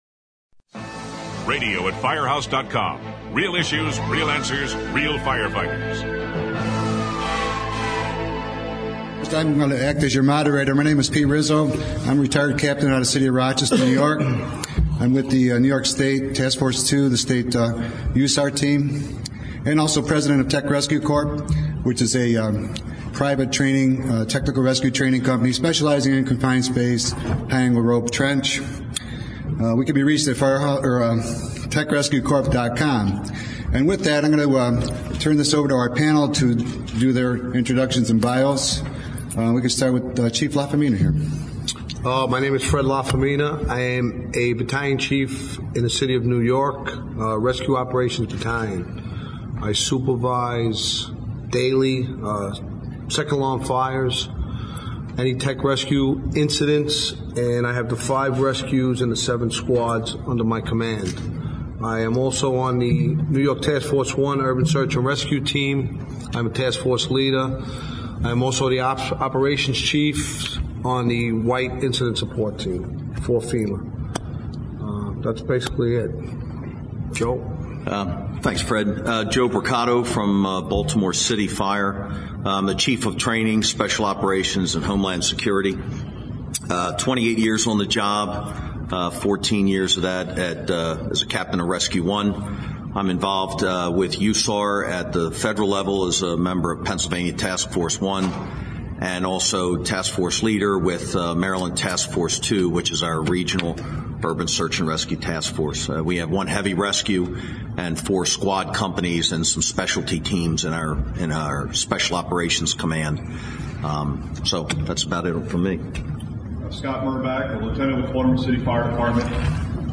This roundtable podcast, recorded at the Firehouse Expo in July, draws from a very experiencd panel on a variety of topics in the specialized rescue field.